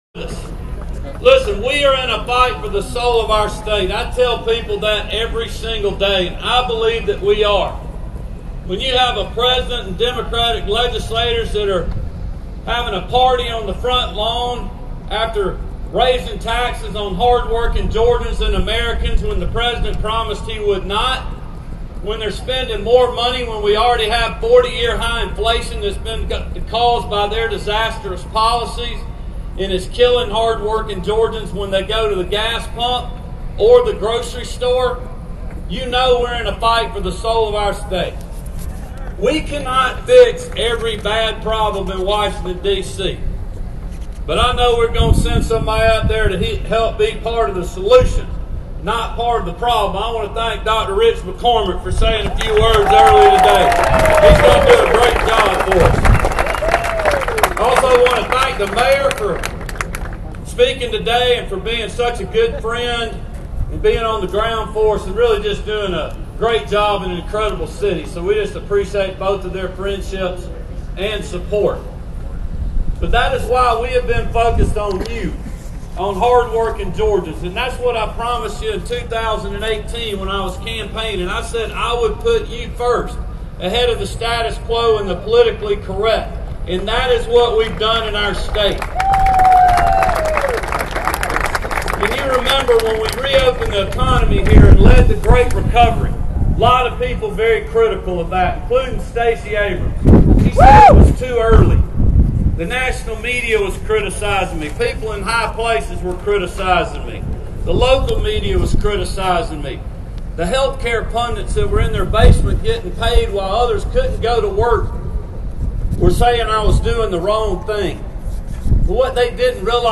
Georgia Governor, Brian Kemp, held a get out the vote rally in the Suburb of Alpharetta featuring Glenn Youngkin, Governor of Virginia
Governor Brian Kemp had get out the vote rally on the Alpharetta Green and was joined by Virgina Governor Glenn Youngkin.